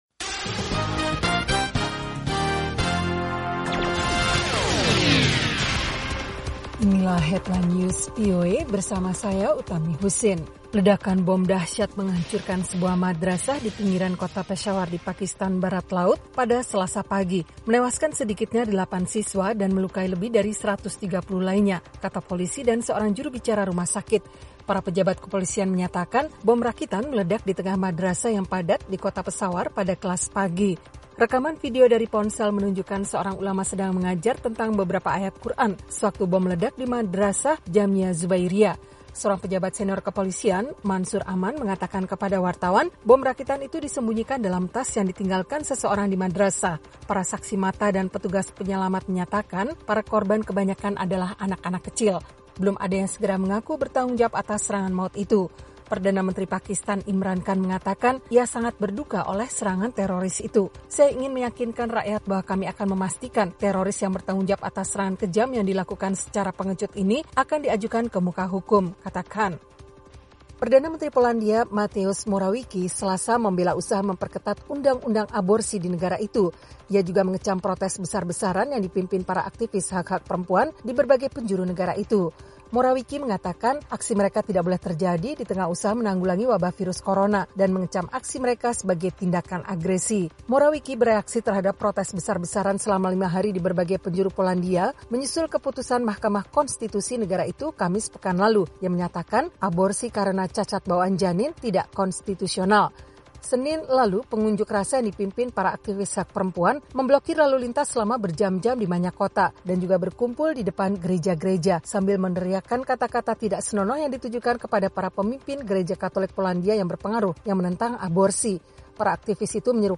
Simak berita terkini langsung dari Washington dalam Headline News, bersama para penyiar VOA, menghadirkan perkembangan terakhir berita-berita internasional.